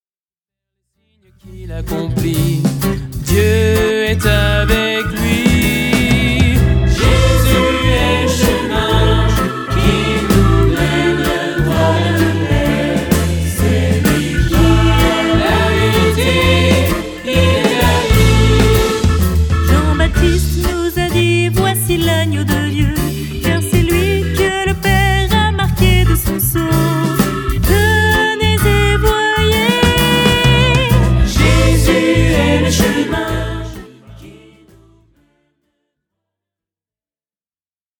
Célèbres chants de Louange